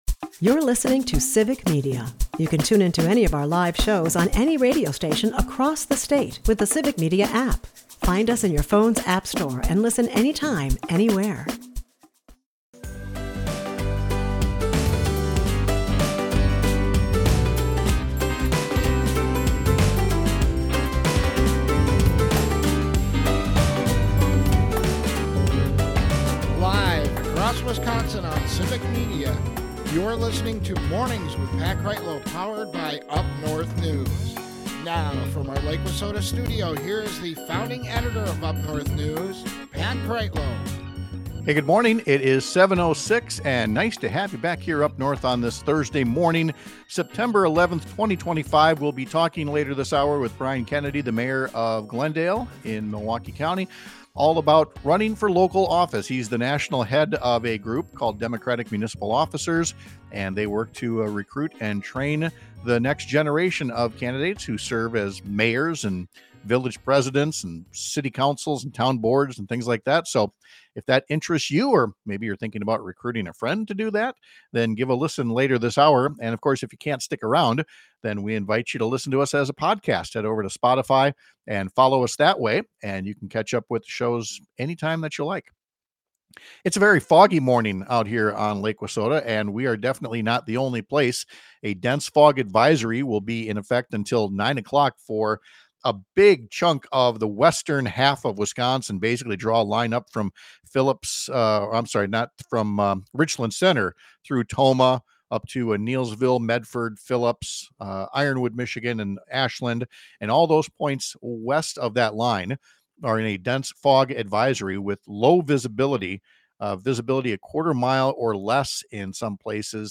It’s time to think about who’s serving on your town board, city council, county board, and in other posts — and if you think it’s a way you could help your community, there’s a group dedicated to training the next round of municipal leaders. We’ll talk to a Wisconsin mayor who heads up that national group.